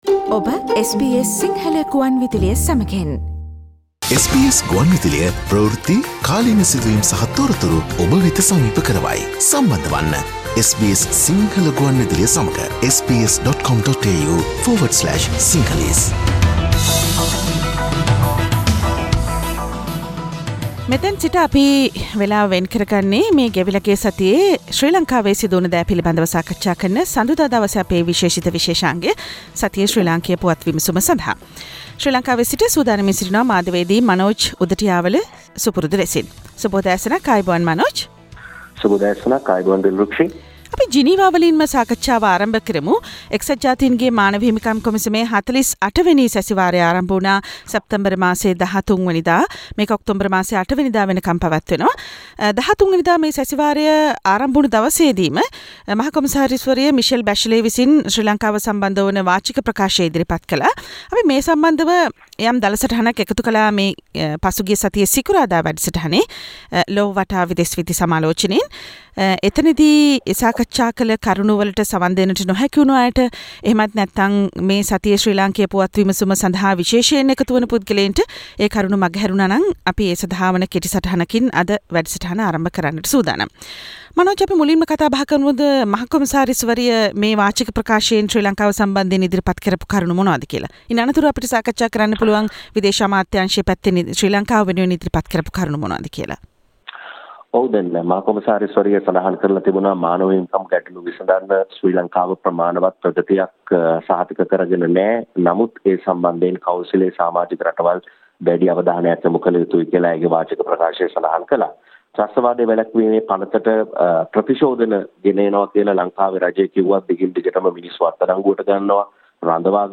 සතියේ ශ්‍රී ලාංකීය පුවත් සමාලෝචනය